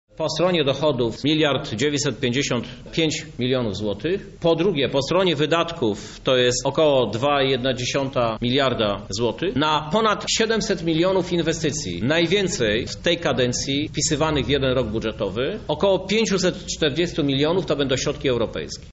Istotne znaczenie dla budżetu ma dofinansowanie europejskie – mówi prezydent miasta Krzysztof Żuk